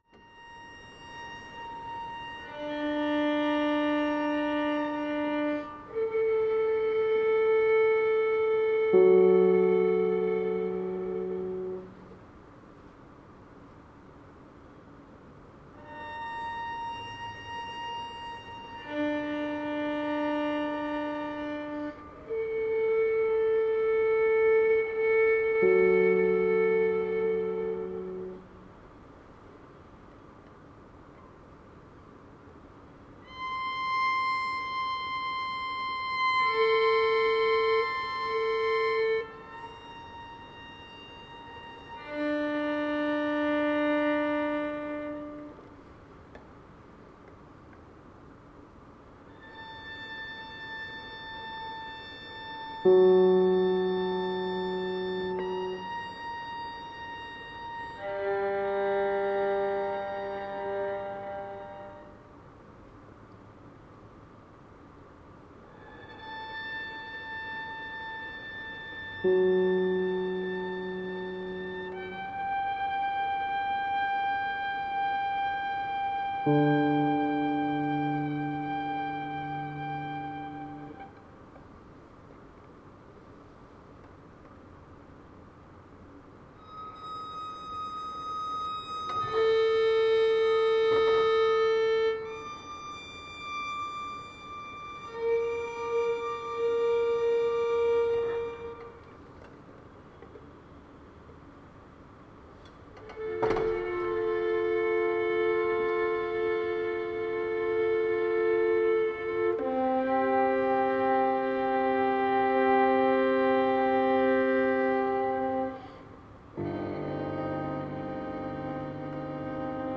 Violin
Piano
LOFI Rehearsal recordings.